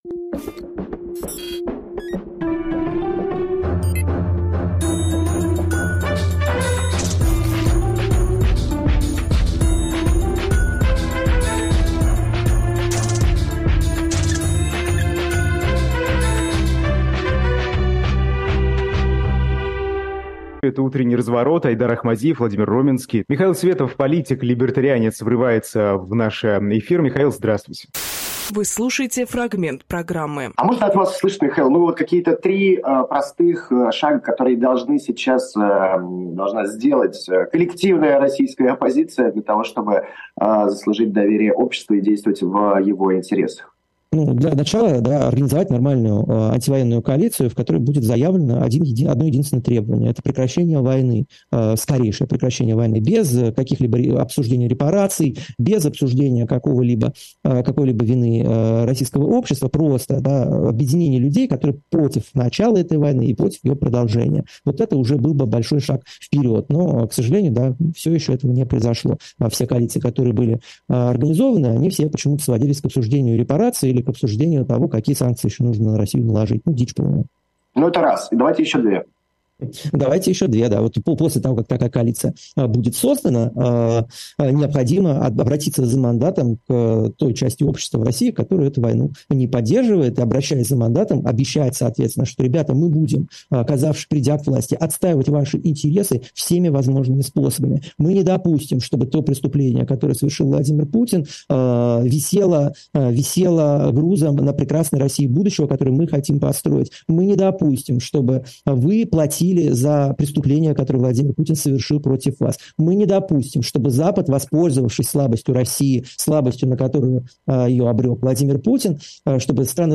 Фрагмент эфира от 13.11